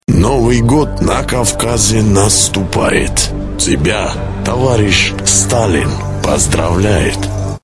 Главная » Рингтоны » Рингтоны пародии